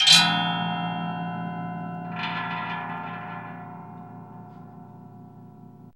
METAL HIT 9.wav